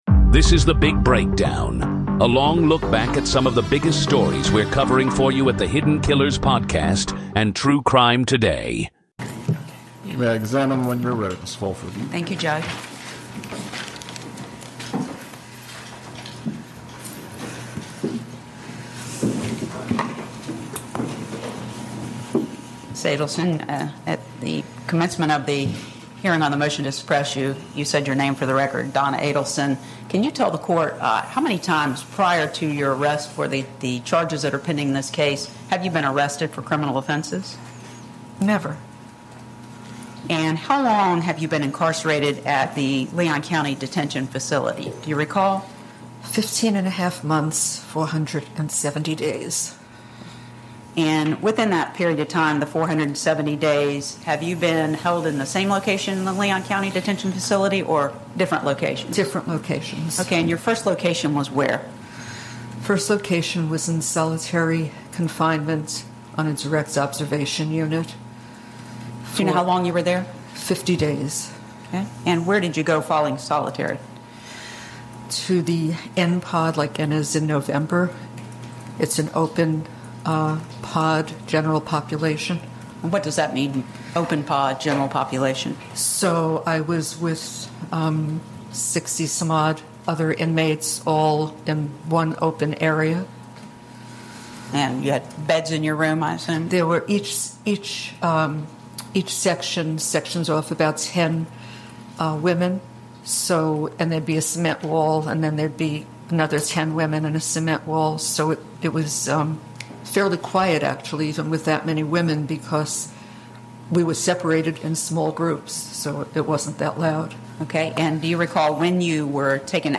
We give you the full testimony as it happened, without spin or commentary.